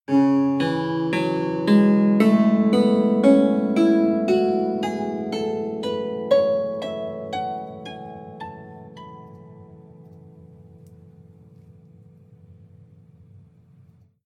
kotamo improvisation sound 2.wav
Original creative-commons licensed sounds for DJ's and music producers, recorded with high quality studio microphones.
kotamo_improvisation_sound_2_s8y.ogg